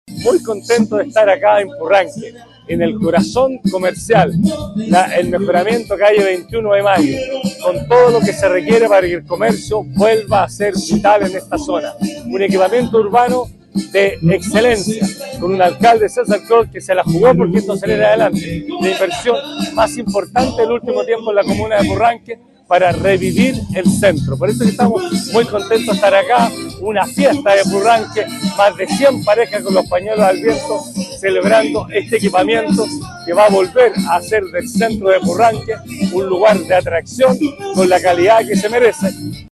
A su vez, el Gobernador Regional de Los Lagos, Patricio Vallespín, enfatizó que es: La inversión más importante del último tiempo en la comuna de Purranque para revivir el centro”.